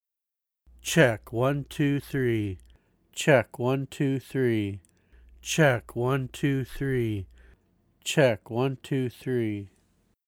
I own an Equation Audio F-20 large diaphragm condenser and a TLM102 large diaphragm condenser. I run the signal either through my Golden Age Pre73jr into the Steinberg UR44 audio interface, or go straight into the interface preamps without the Golden Age pre in line.
In each sample, I say "Check one two three" four times.
The first sample mp3 compares the F-20 in the interface pre with the TLM102 in the Golden Age pre. First "check one two three" is the F-20 Steinberg pre, second is the TLM102 Golden Age pre (gain at 65), third is the F-20 Steinberg pre, and fourth is the TLM102 Golden Age pre.
Overall, I prefer the sound of the TLM102 over the F-20 (for male voice).